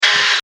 Drill